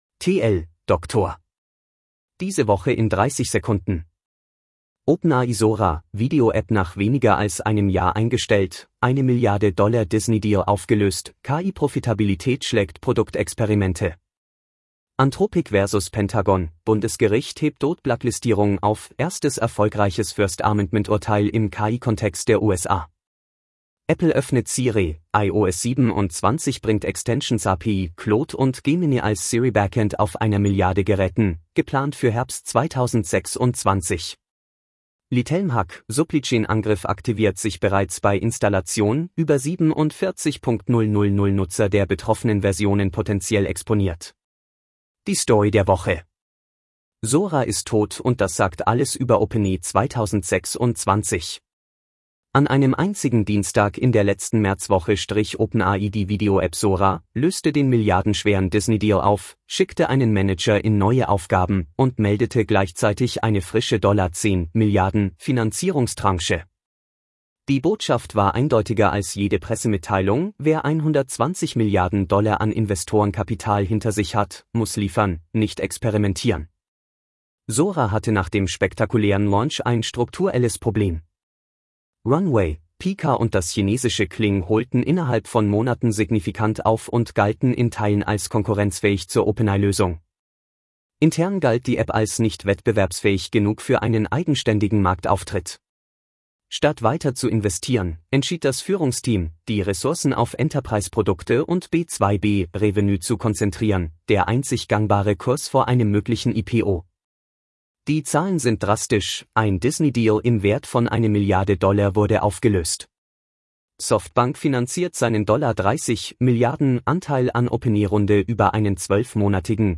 Vorgelesen mit edge-tts (de-DE-ConradNeural)